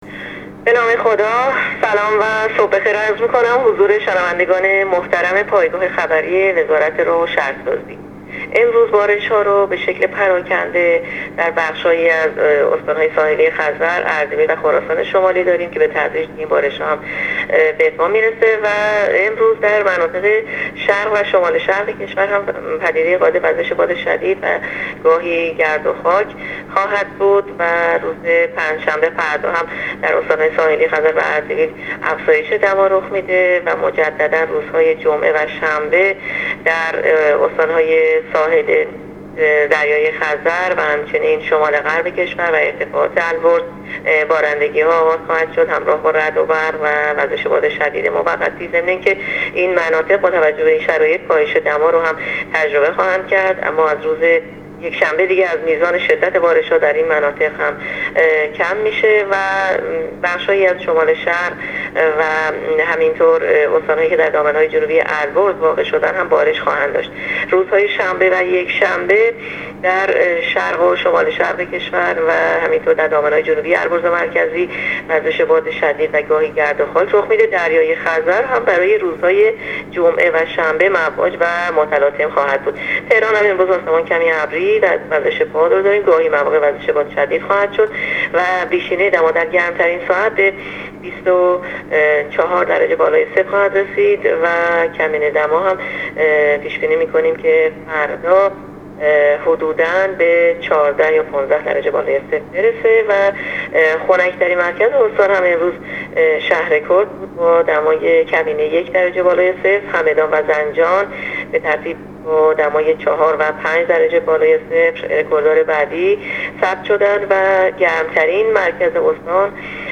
گزارش رادیو اینترنتی پایگاه خبری از آخرین وضعیت آب‌وهوای بیست و هفتم مهر ماه؛